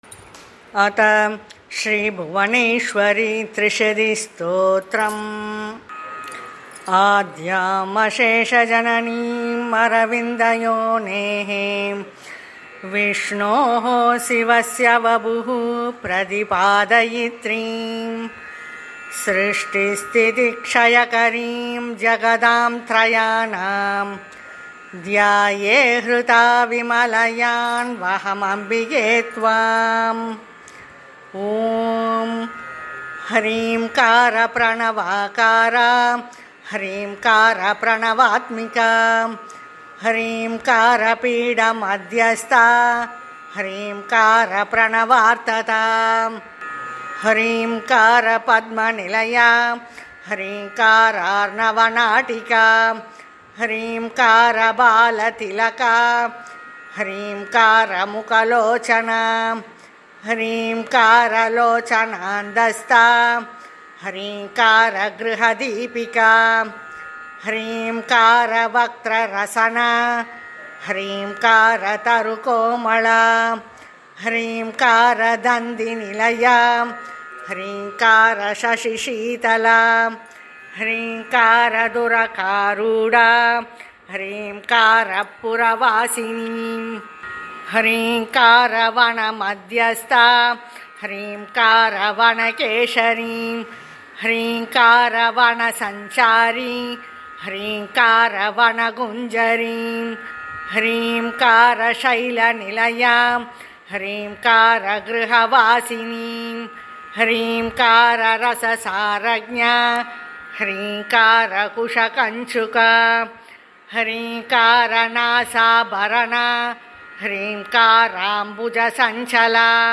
Devotional rendition of Sri Bhuvaneshwari Trisathi Stotram with Sanskrit and Tamil lyrics. Listen to the audio chant and experience the grace of Goddess Bhuvaneshwari.